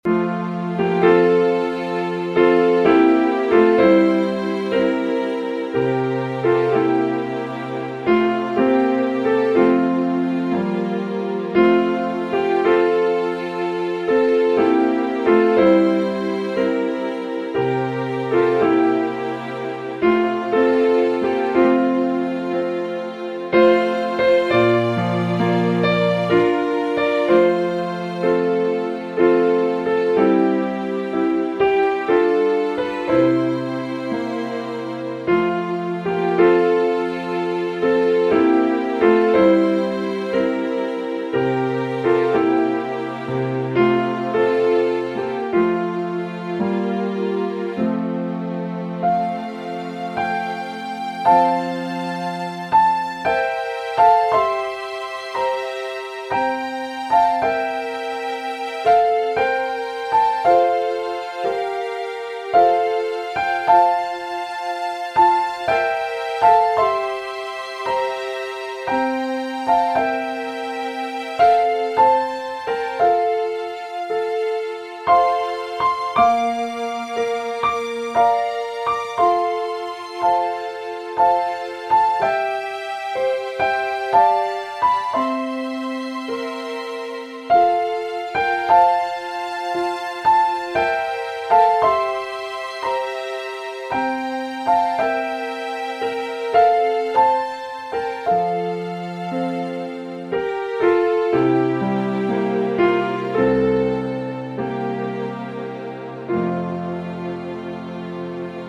Hymn,Sing - AWR - Instrumental Music - Podcast